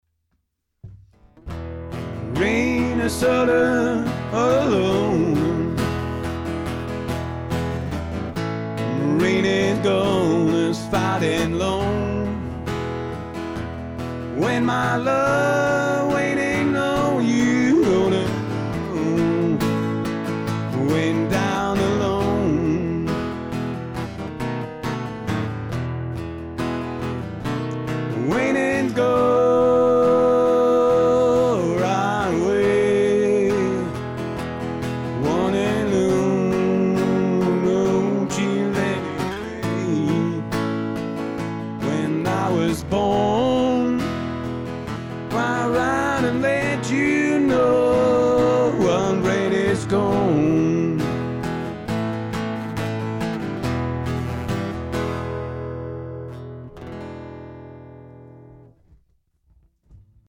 Ist halt störend, denn ich muss mich ja mit dem Ding vertraut machen, bevor ich auf die Bühne gehe, oder dann eben beim Auftritt üben Anbei die Testaufnahme aus Cubase (unbearbeitet) - der zweistimmige Gesang ist schon geil.